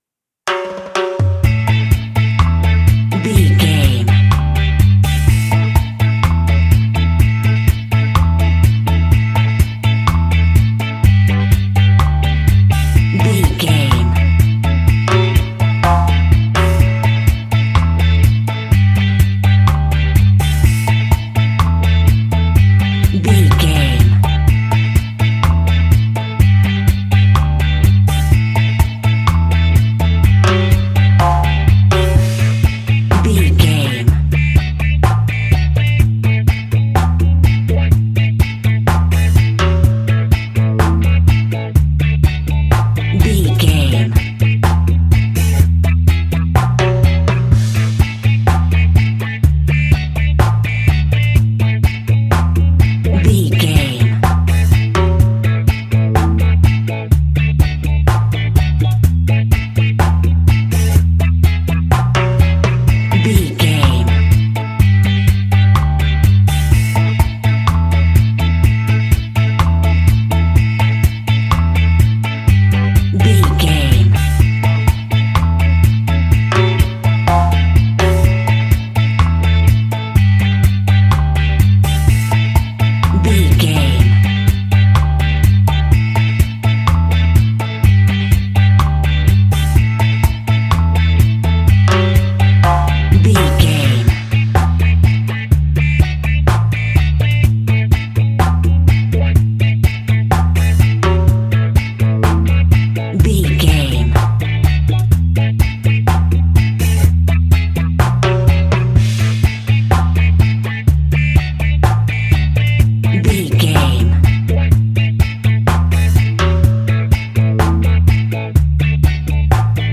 Classic reggae music with that skank bounce reggae feeling.
Uplifting
Ionian/Major
instrumentals
laid back
chilled
off beat
drums
skank guitar
hammond organ
percussion
horns